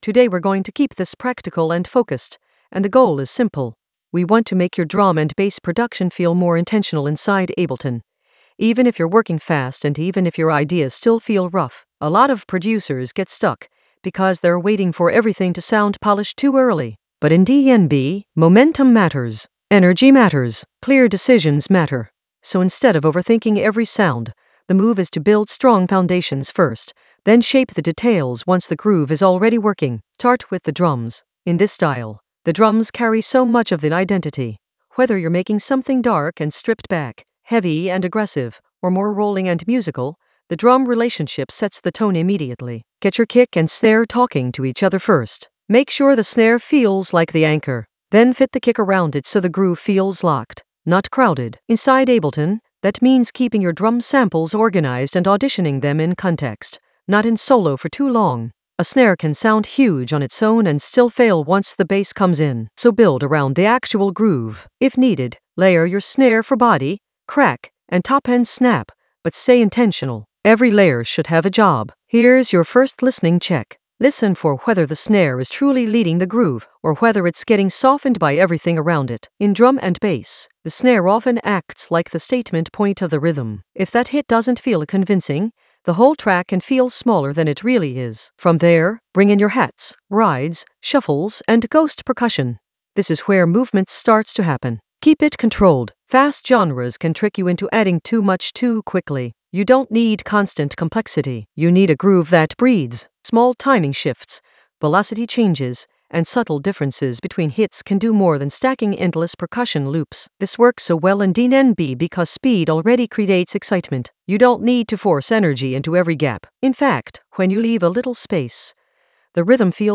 An AI-generated beginner Ableton lesson focused on Brockie sub bass that rinse in the Basslines area of drum and bass production.
Narrated lesson audio
The voice track includes the tutorial plus extra teacher commentary.